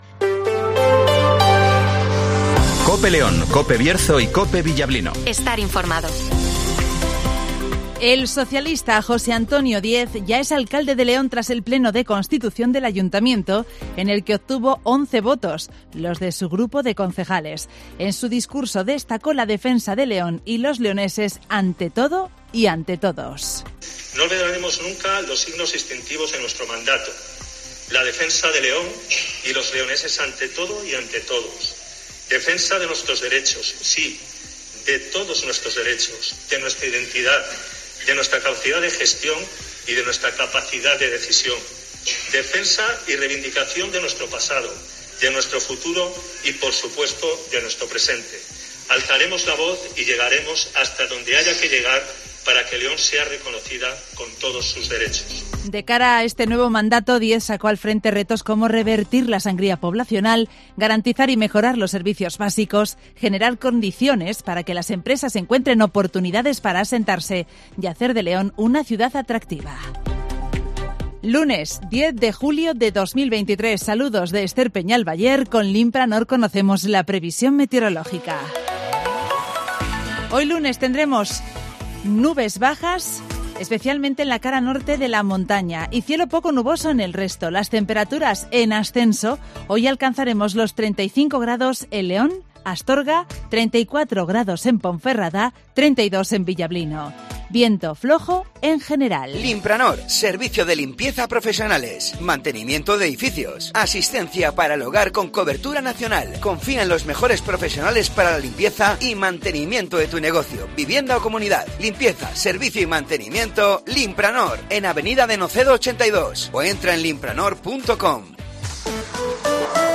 - Informativo Matinal 08:25 h